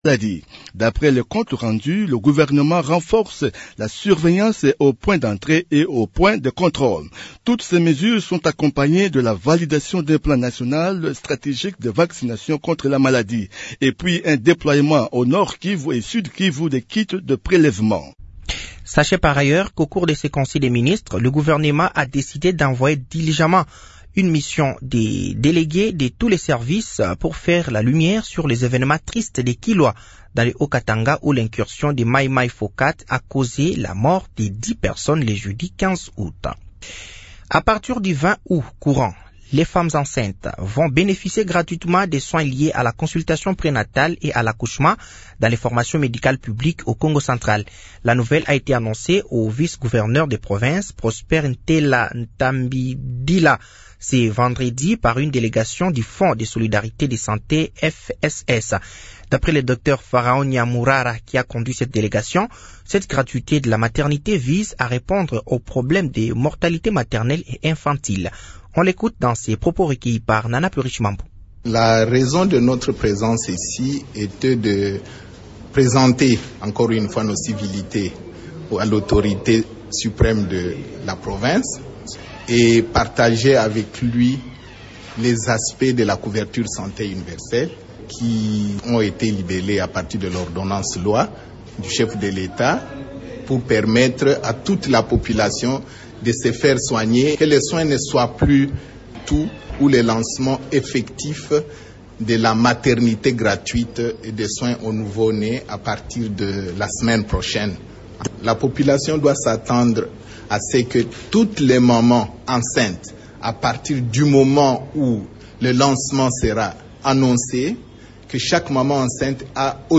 Journal français de 15h de ce samedi 17 août 2024